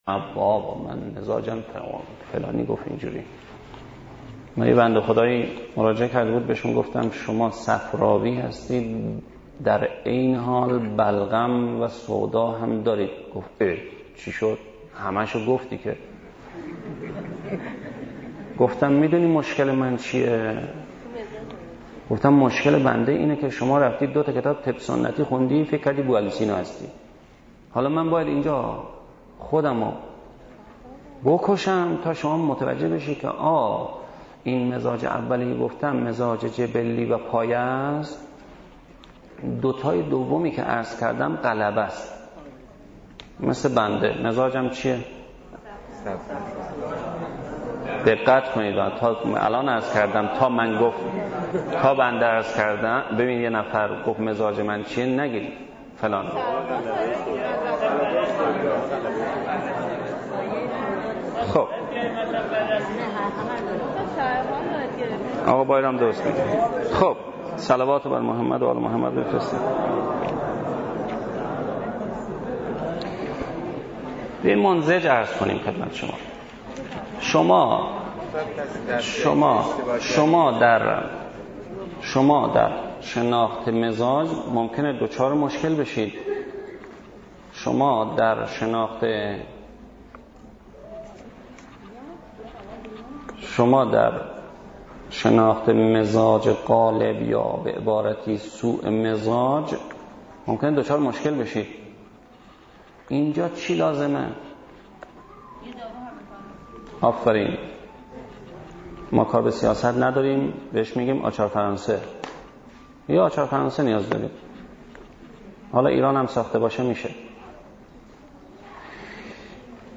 صوت جلسات طب سنتی اسلامی ، 27/ 1/ 95 (2)